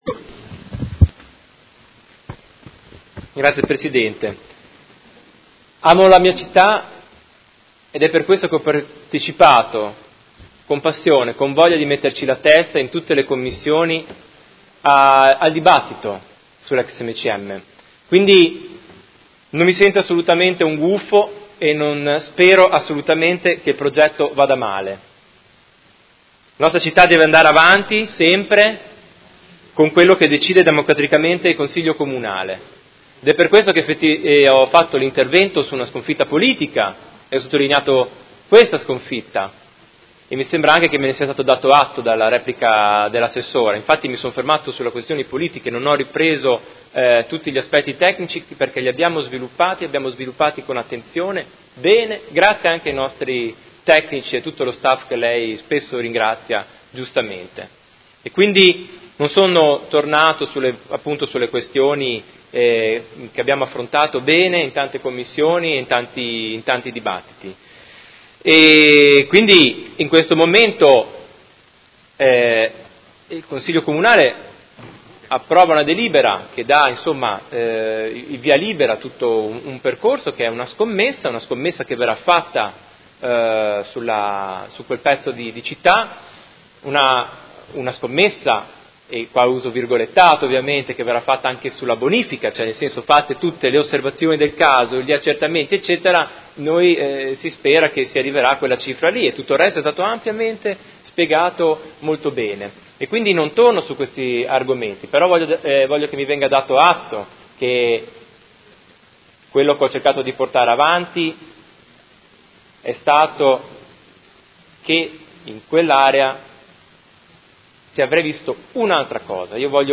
Seduta del 04/04/2019 Dichiarazione di voto.